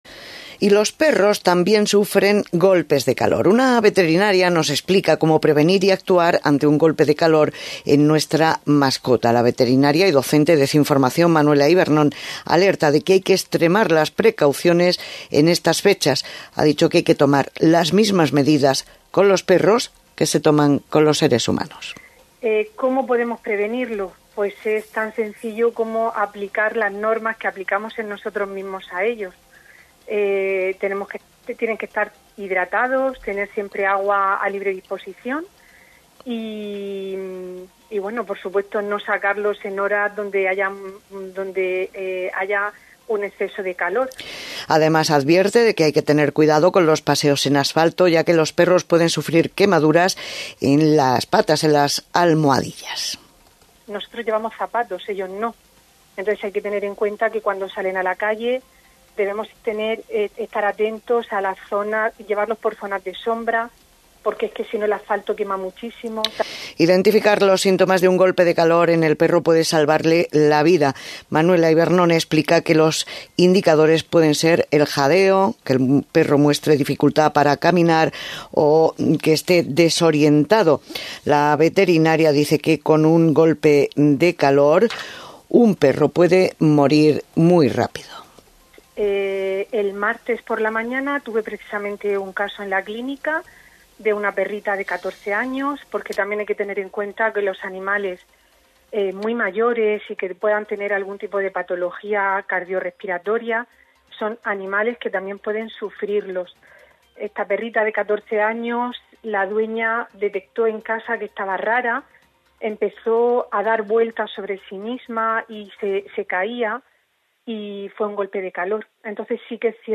En este extracto puedes escuchar sus recomendaciones. Al final se trata de aplicar las mismas medidas que aplicamos con nosotros mismos.